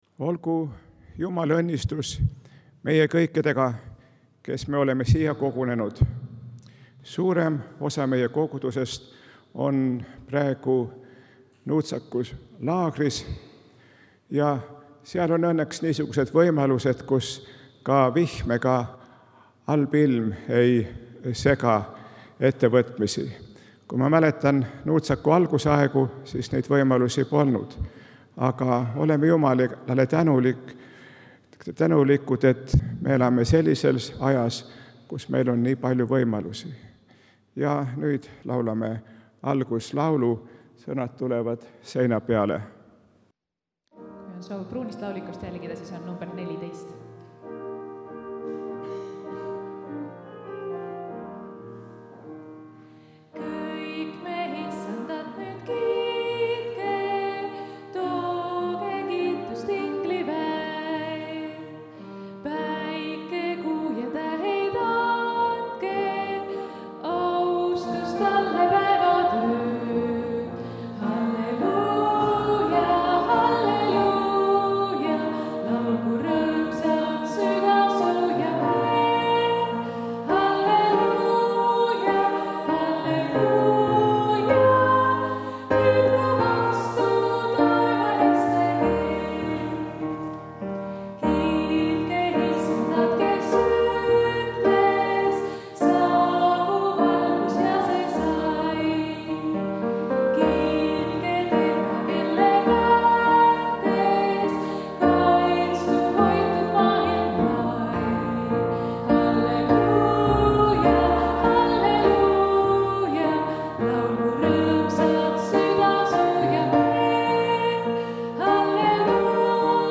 Pühakirja lugemine
Lühijutlus
Jutlus